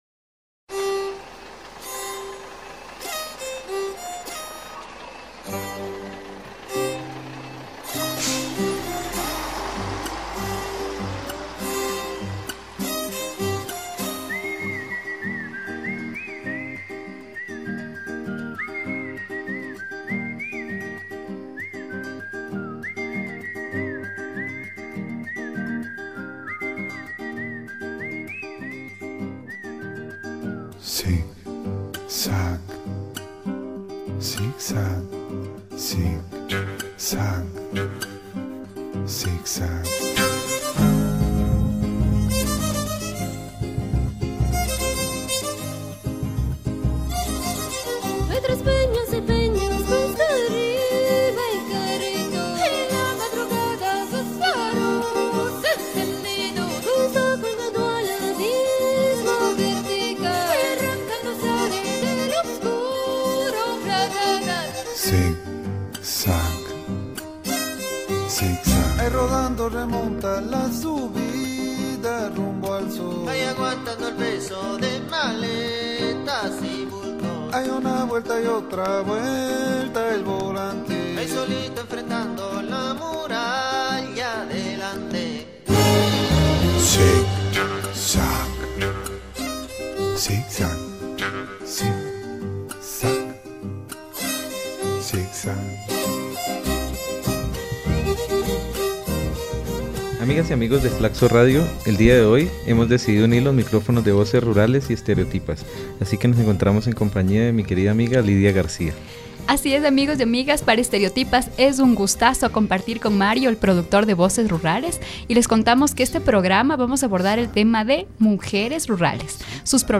con ellas estaremos dialogando respecto a mujeres y ruralidad en Ecuador.